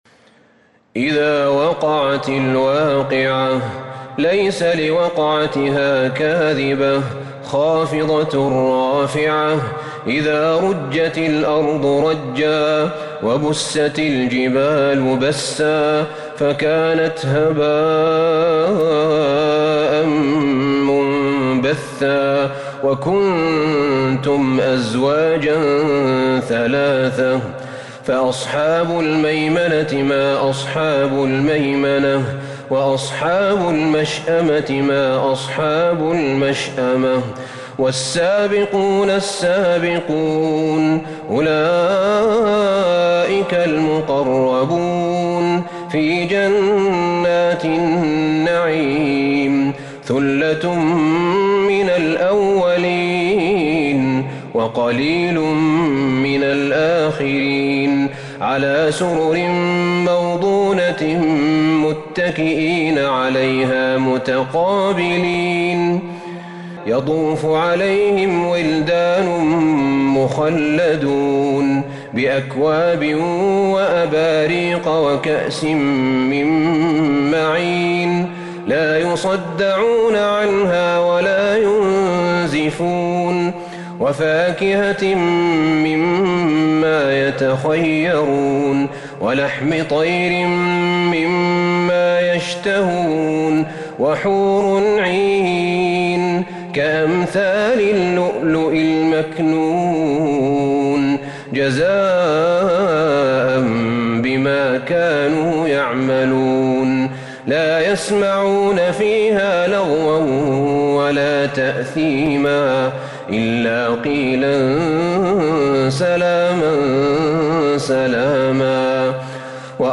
سورة الواقعة Surat Al-Waqi'ah من تراويح المسجد النبوي 1442هـ > مصحف تراويح الحرم النبوي عام 1442هـ > المصحف - تلاوات الحرمين